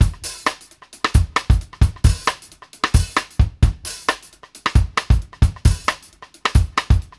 X-STICK FU-R.wav